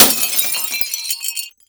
GLASS_Window_Break_04_mono.wav